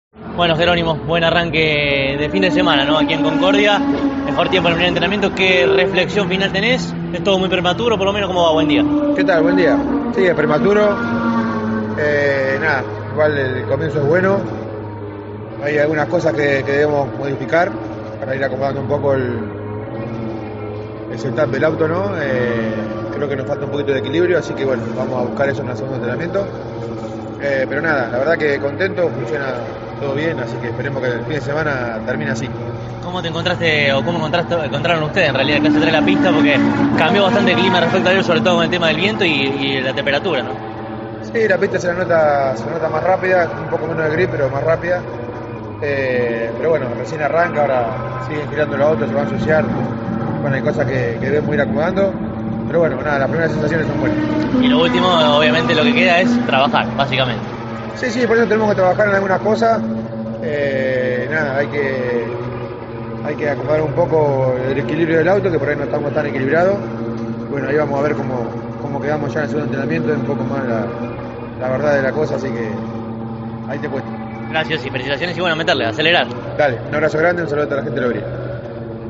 La palabra del referente